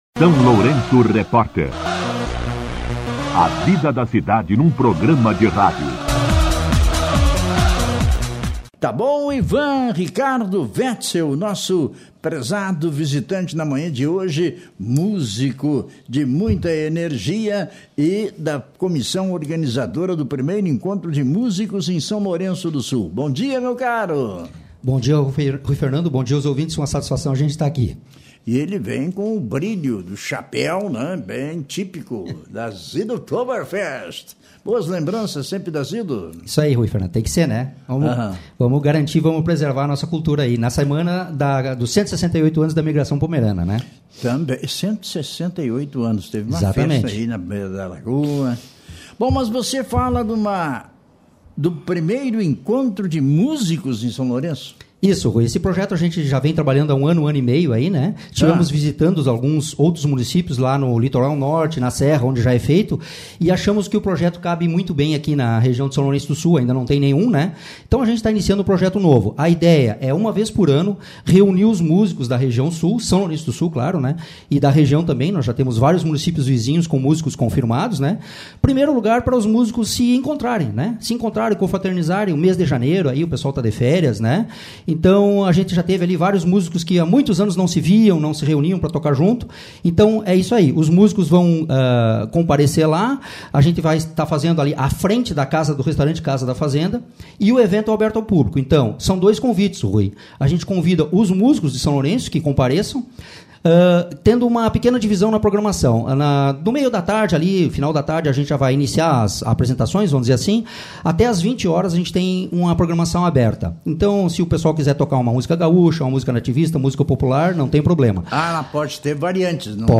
O 1º Encontro de Músicos de São Lourenço do Sul ocorre nesta sexta-feira, a partir das 18h. O evento foi destaque na programação da SLR RÁDIO, que recebeu os músicos